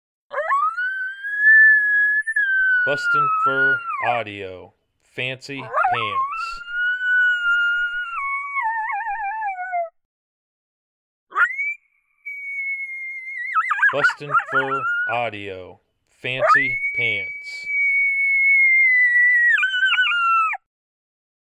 BFA’s MotoMoto doing his thing, howling with enthusiasm that for sure fires up the wild coyotes.
• Product Code: howls